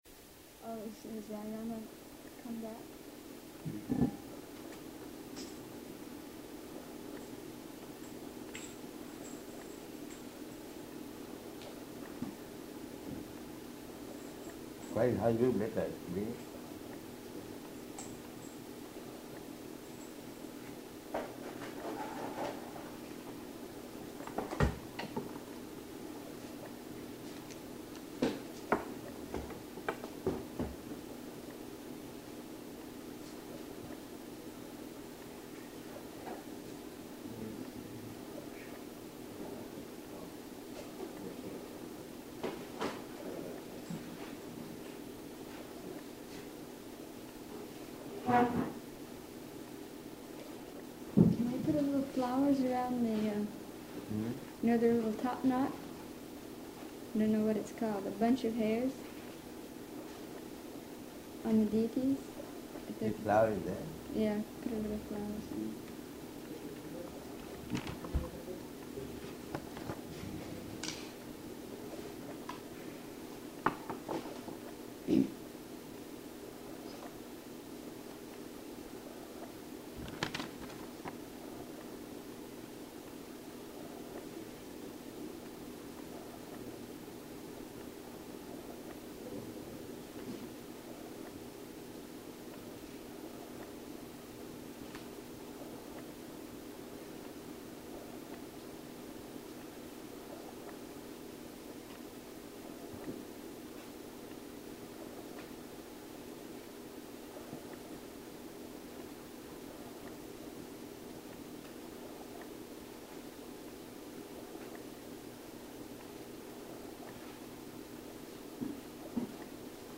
Room Conversation about New Vrindavan
Location: Honolulu